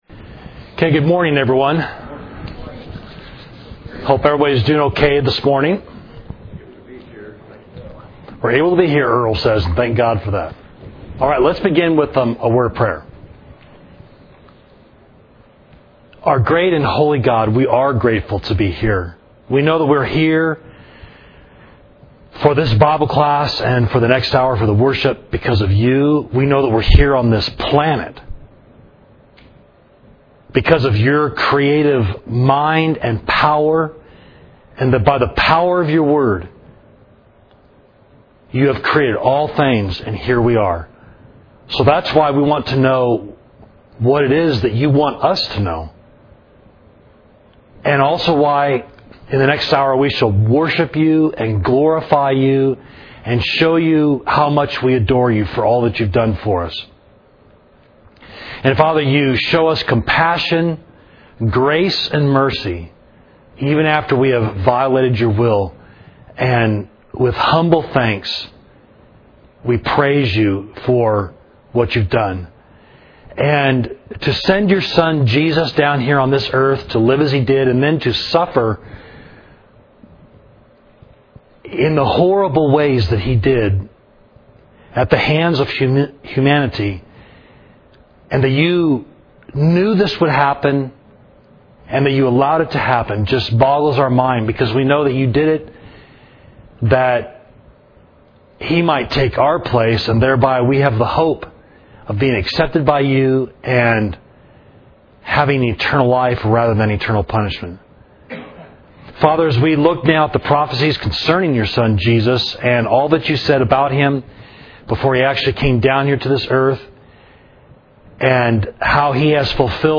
Class: Messianic Prophecies, Psalms – Savage Street Church of Christ